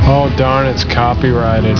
- MST3K Joel: "Oh darn it's copyrite", usually used when making a bookmark on AOL.